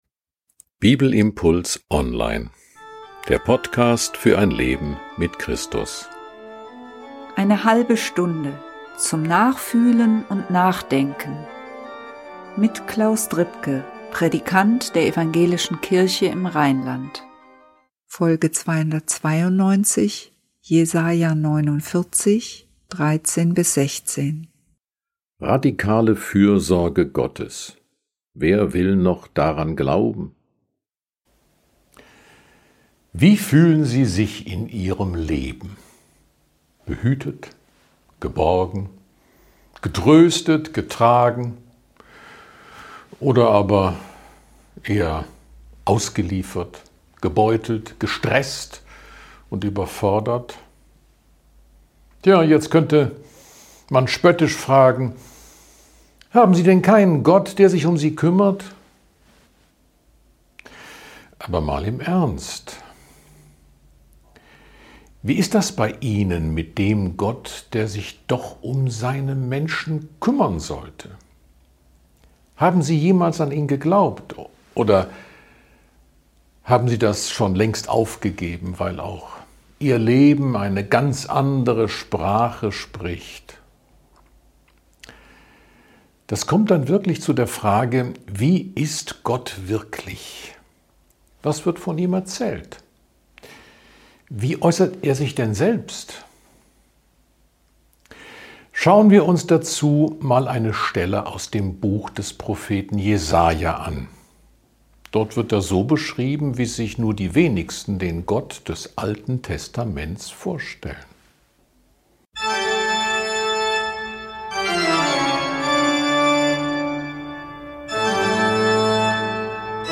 Die Welt sieht nicht so aus, als ob Gott sich um sie und um die Menschen kümmern würde - oder? Ist das so, oder glauben wir das nur, weil wir mit dieser Welt nicht einverstanden sind? Ein Bibelimpuls zu Jesaja 49, 13-16